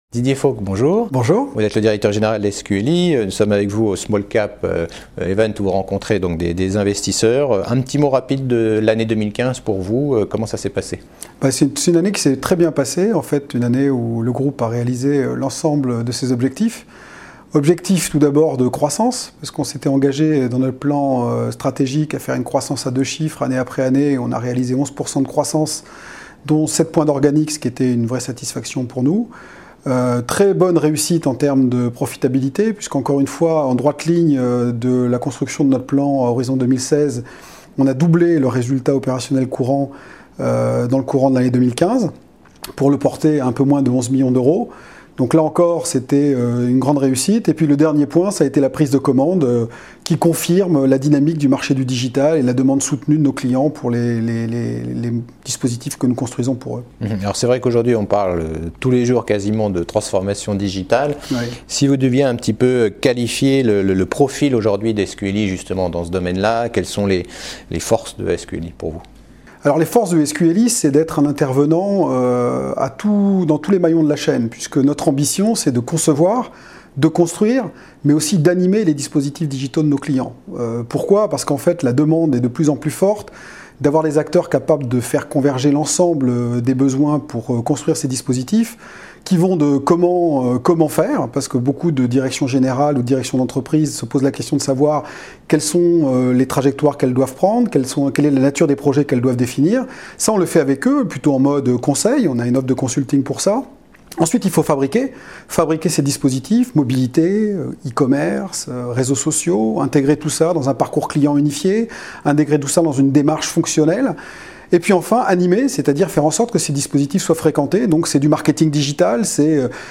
La Web Tv partenaire media du SmallCaps Event organisé par CF&B Communication à Paris pour des interviews de dirigeants.